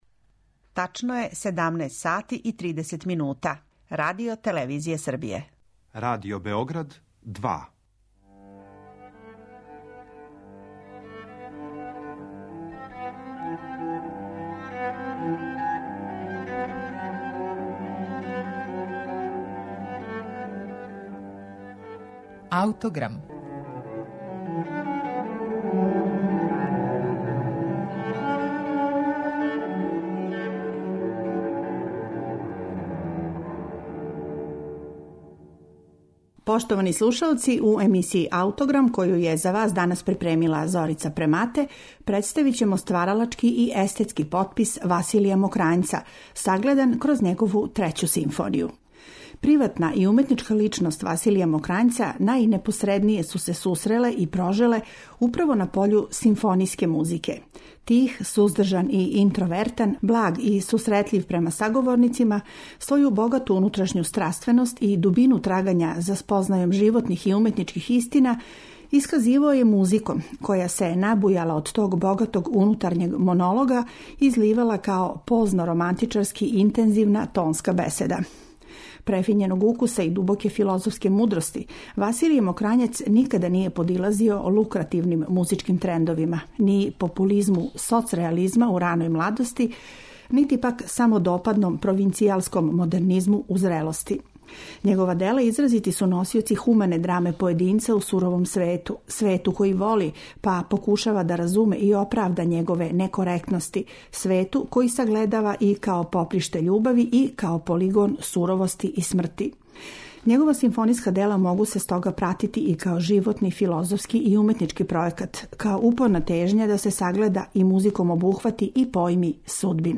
Београдском филхармонијом тада је дириговао Живојин Здравковић.